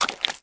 High Quality Footsteps
STEPS Swamp, Walk 22.wav